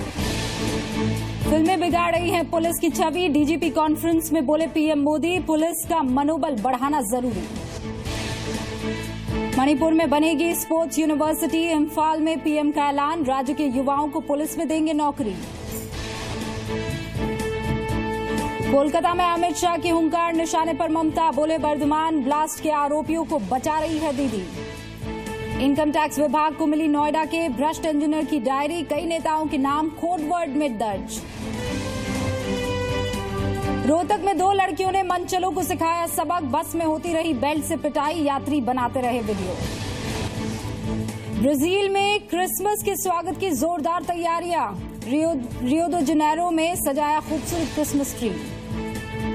Top news headlines at 5 pm
Listen to the top news headlines at 5 pm